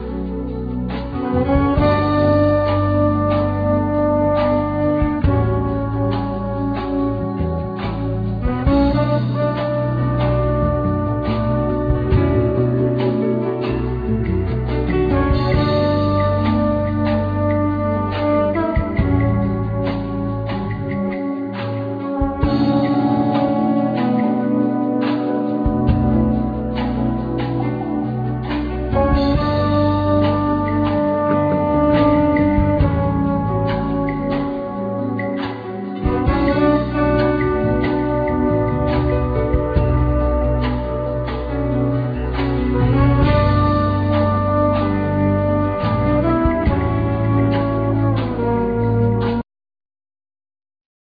Bass
Trumpet
Keyboard
Guitar
Fretless Bass
Drums
Percussions
Accordion
Trombone
Alto saxophone